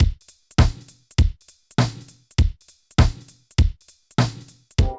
110 DRM LP-L.wav